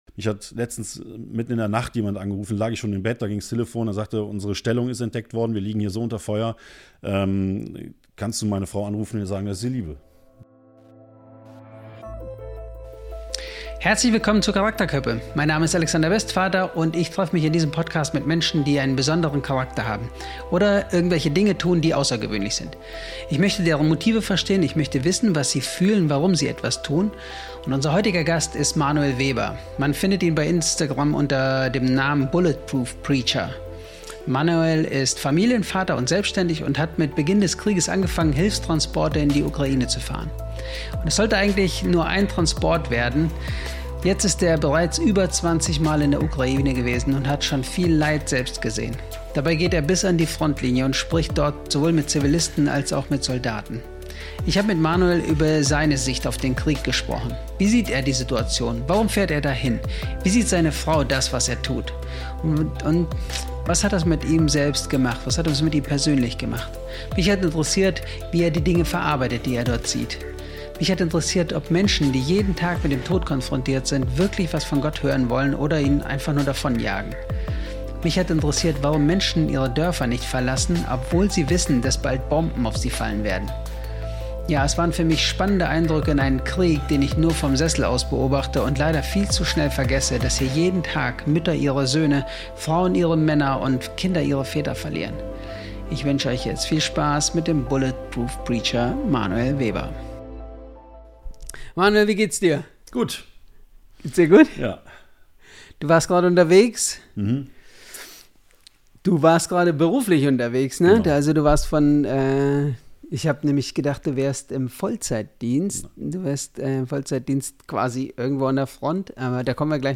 Wir sprechen über Gott im Angesicht des Todes, über Menschen, die bleiben, obwohl Bomben fallen – und über einen Krieg, den wir oft viel zu schnell wieder vergessen. Ein ehrliches, eindringliches Gespräch über Mut, Verantwortung und Menschlichkeit mitten im Chaos.